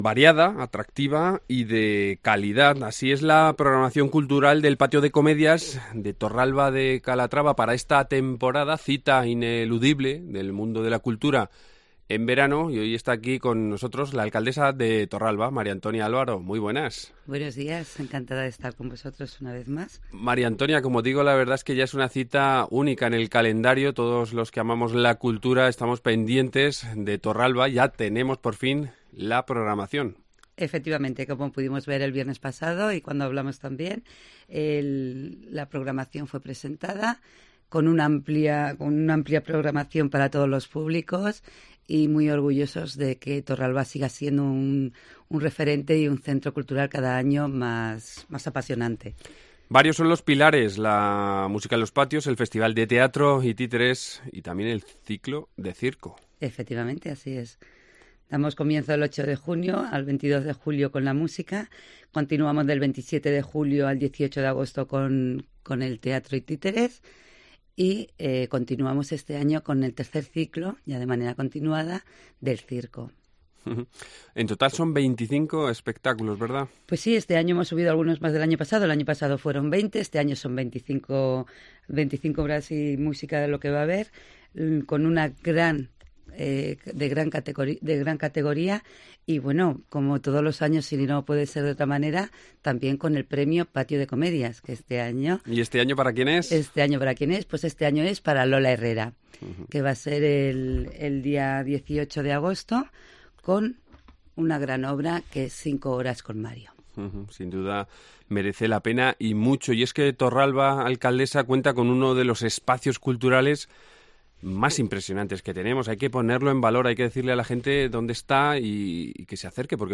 María Antonia Álvaro, alcaldesa
Así será la programación cultural del Patio de Comedias de Torralba de Calatrava esta temporada, que vuelve a contar como pilares fundamentales con la Música en los patios, el VIII Festival de Teatro y Títeres Patio de Comedias y el III Ciclo de Circo. Hablamos con la alcaldesa, María Antonia Álvaro.